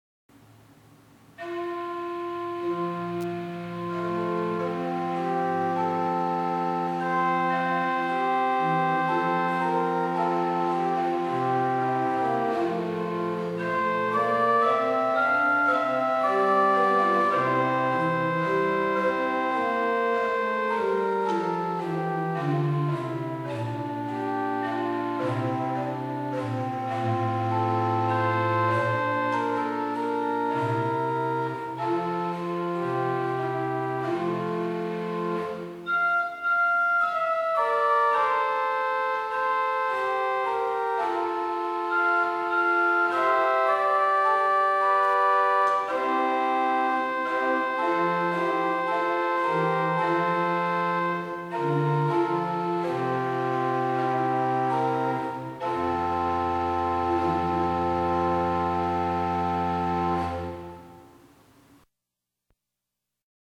The sound, in fact, is very similar to a Tannenberg organ.
Listen to an improvisation on the Principal 8' by clicking